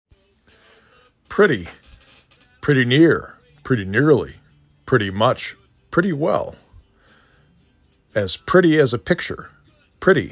pret ty
p r i t E